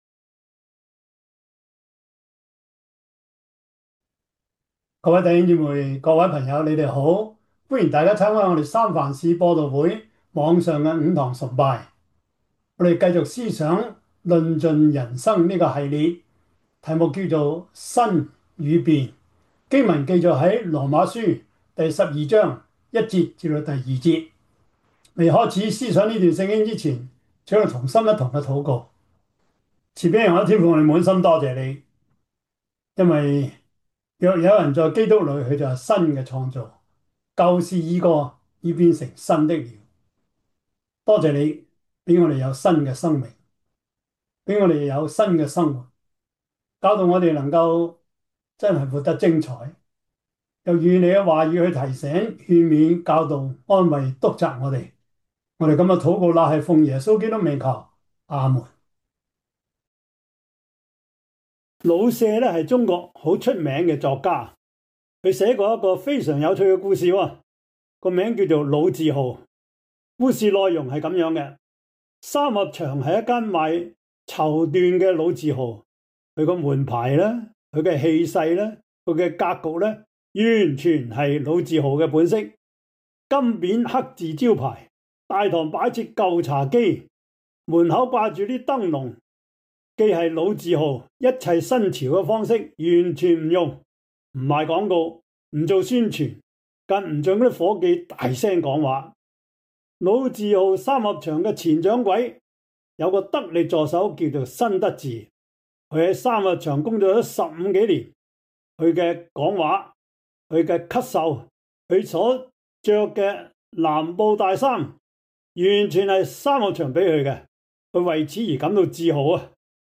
羅馬書 12:1-2 Service Type: 主日崇拜 羅馬書 12:1-2 Chinese Union Version